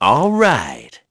Crow-Vox_Happy4.wav